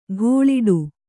♪ ghōḷiḍu